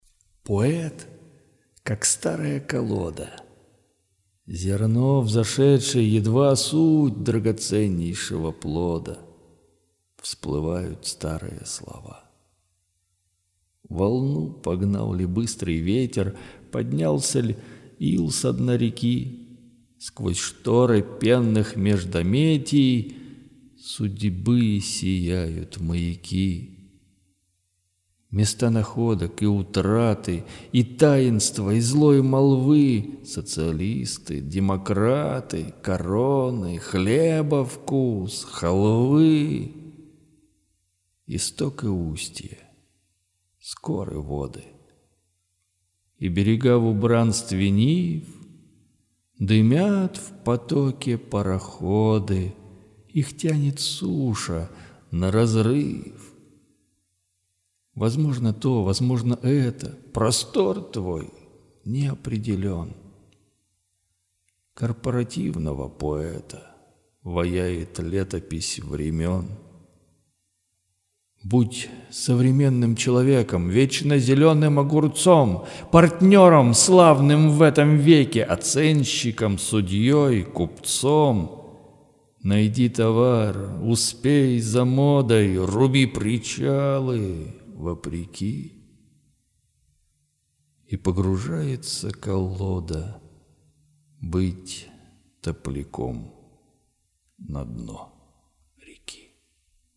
• Стихотворение: Лирика
декламация